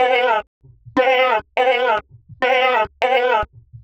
Index of /90_sSampleCDs/Sample Magic - Transmission-X/Transmission-X/transx loops - 125bpm